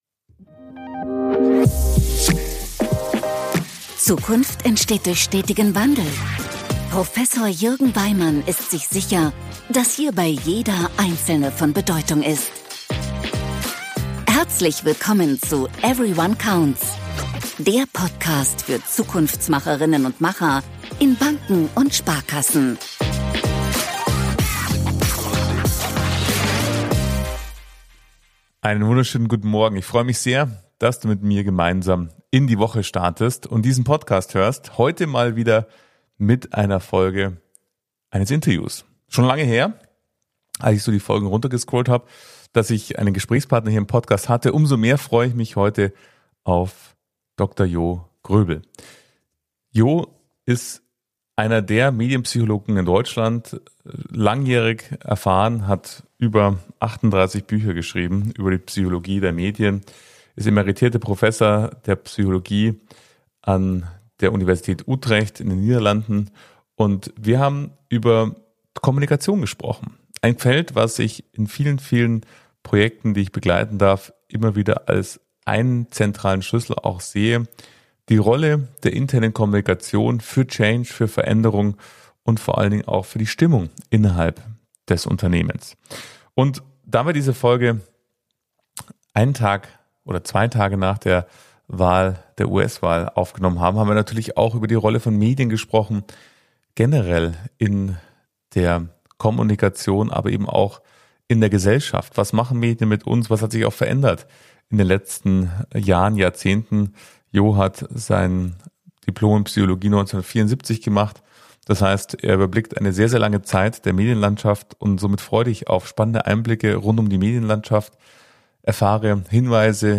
Die Bedeutung der internen Kommunikation für Change - Interview mit Medienpsychologe Dr. Jo Groebel ~ Everyone Counts - Transformation für Banken und Sparkassen Podcast